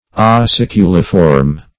Meaning of aciculiform. aciculiform synonyms, pronunciation, spelling and more from Free Dictionary.